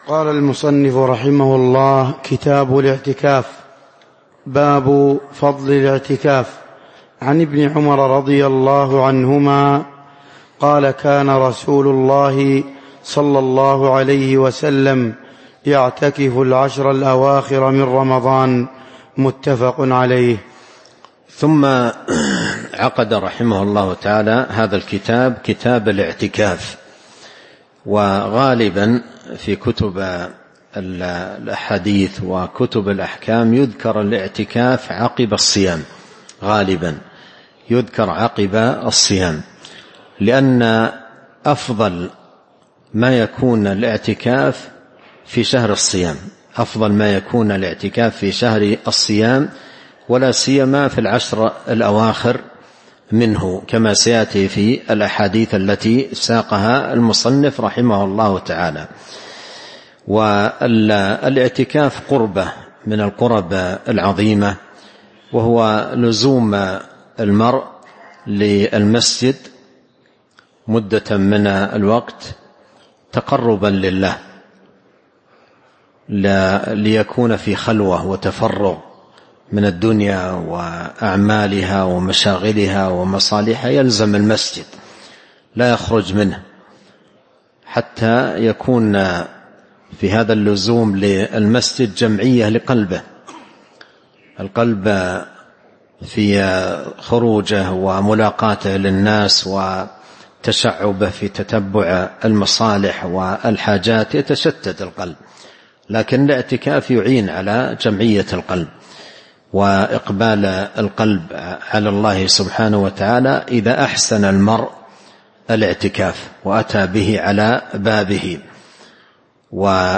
تاريخ النشر ١٣ جمادى الآخرة ١٤٤٥ هـ المكان: المسجد النبوي الشيخ